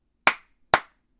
clap@2